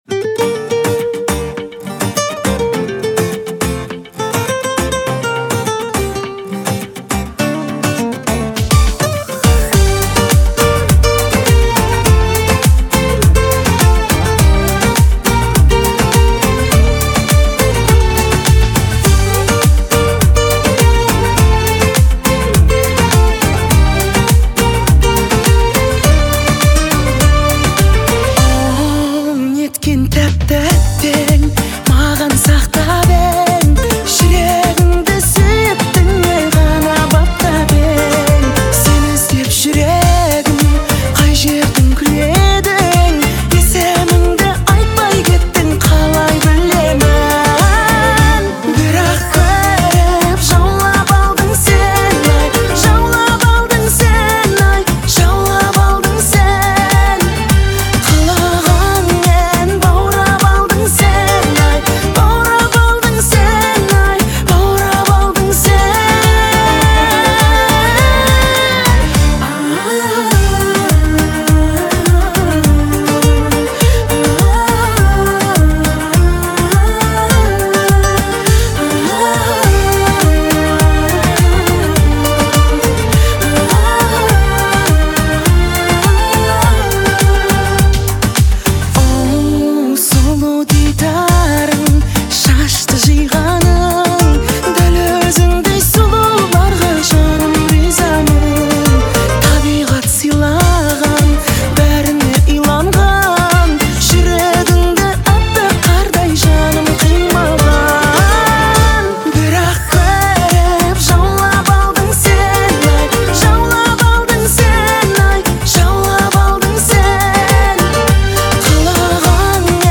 это яркая и запоминающаяся песня в жанре поп